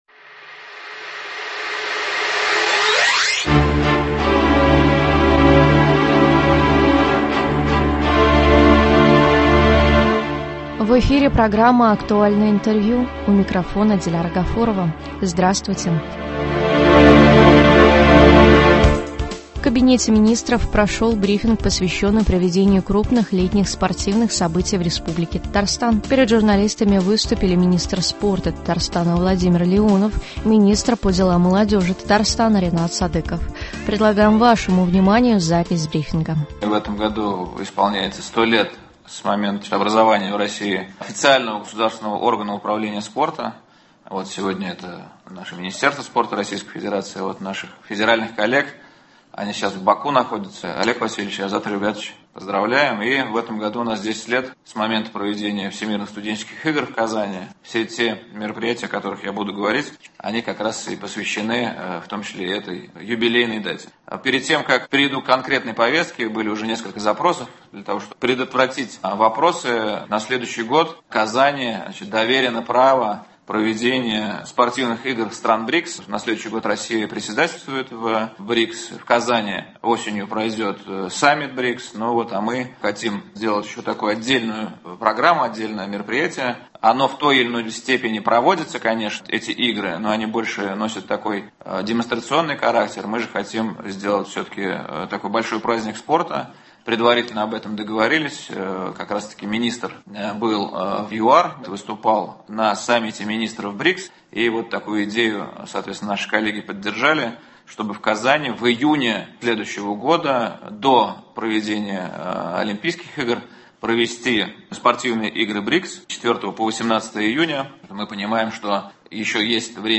В Кабинете министров прошел брифинг, посвящённый проведению крупных летних спортивных событий в Республике Татарстан.
Министр спорта Республики Татарстан Владимир Леонов